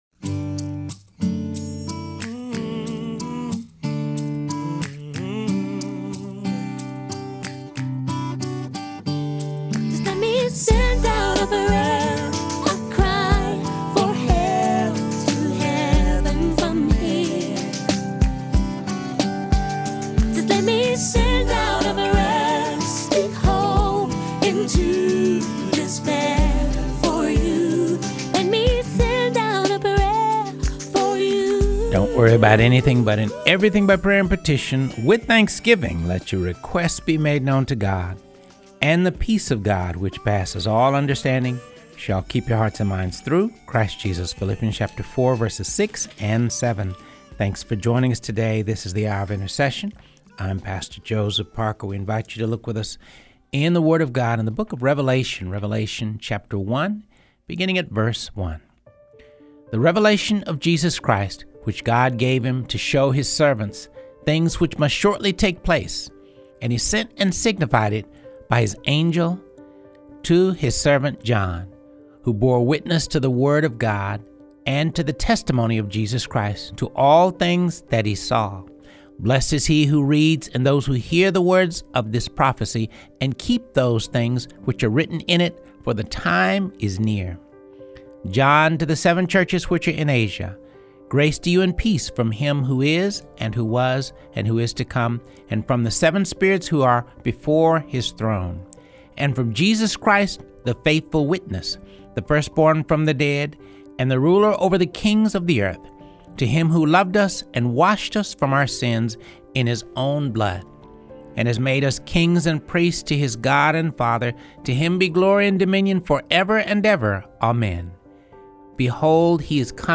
reads passages from the Bible on this episode of Hour of Intercession